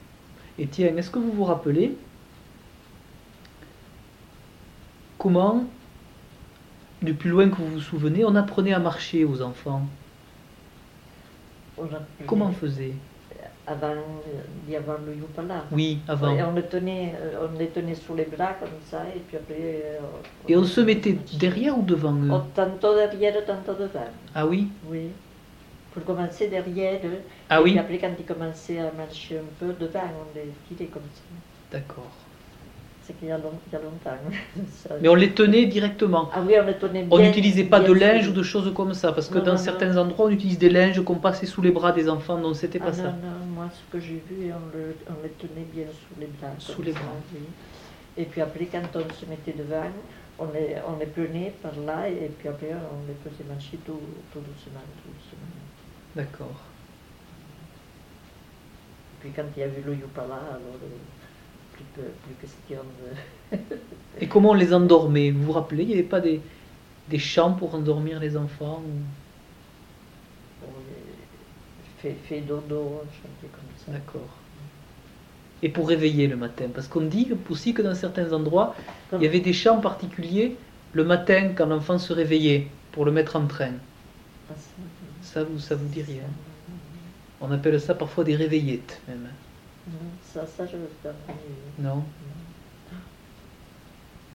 Aire culturelle : Couserans
Lieu : Alas (lieu-dit)
Genre : récit de vie